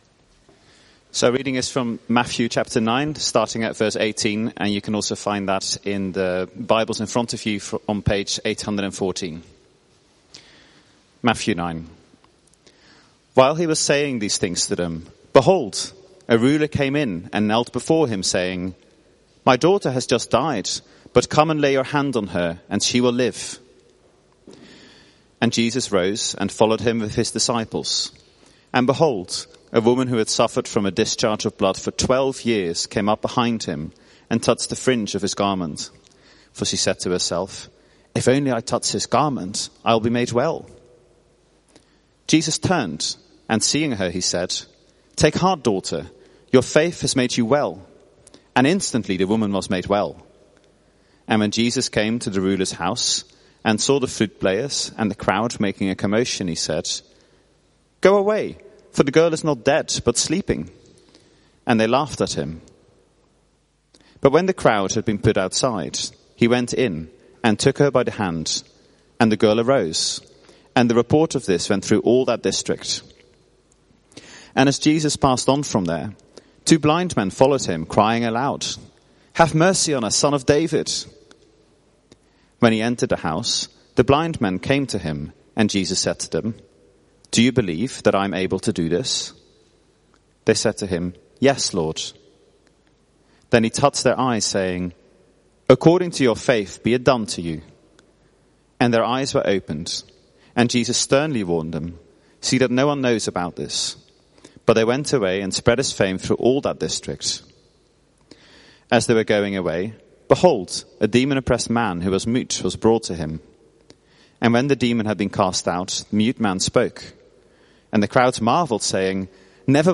A talk recording from our series in Matthew's gospel, at Euston Church